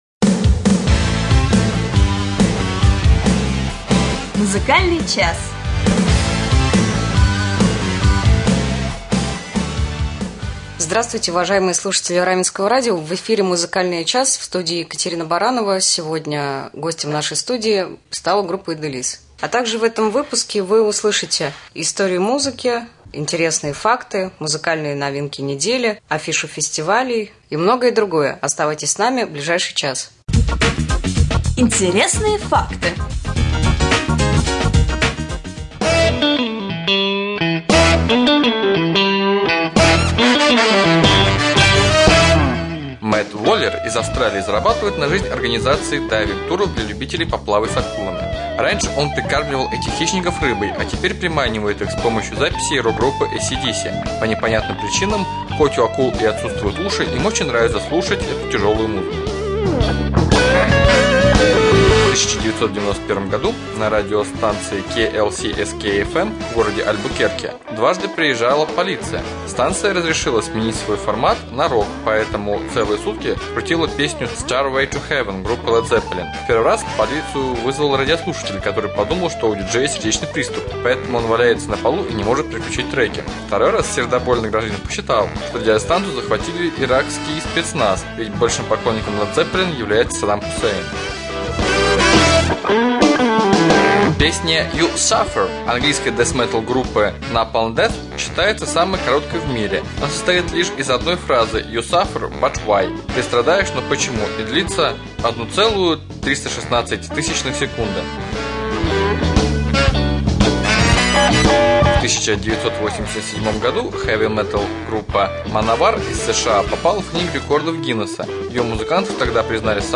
В студии музыканты группы «ИделИс»